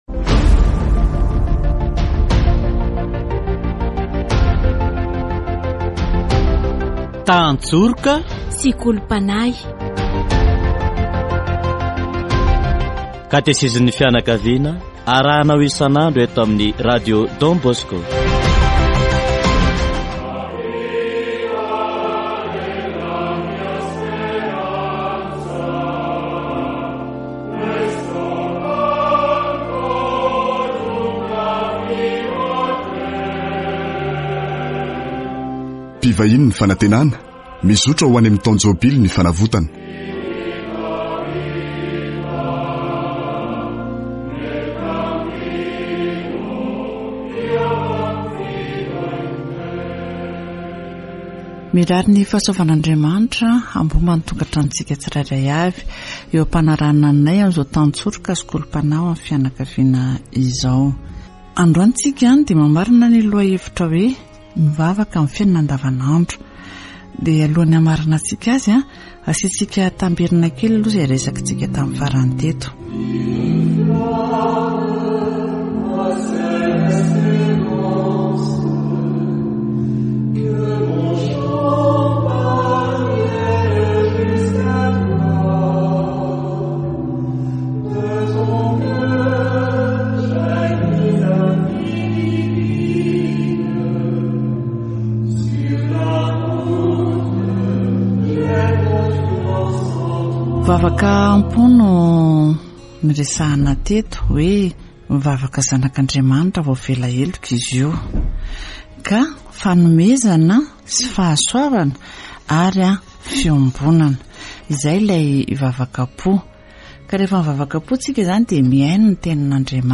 Katesizy momba ny "mivavaka amin'ny fiainana andavanandro"